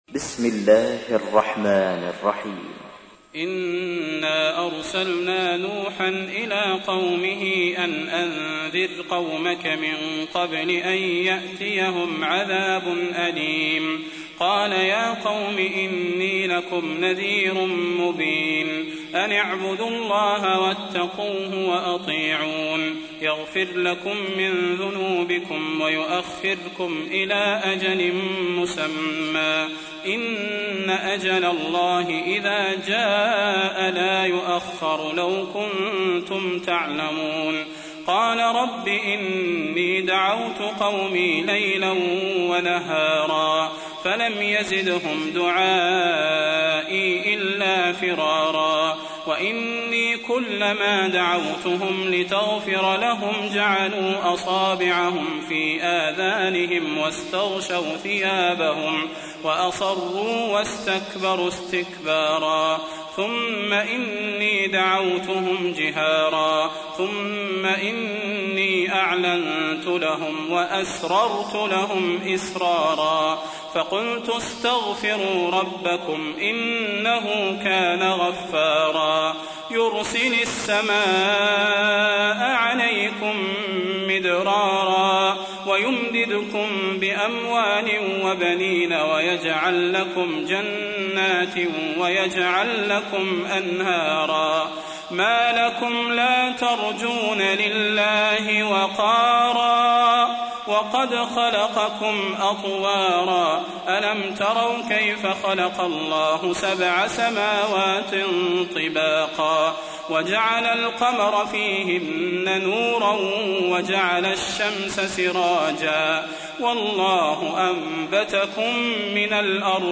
تحميل : 71. سورة نوح / القارئ صلاح البدير / القرآن الكريم / موقع يا حسين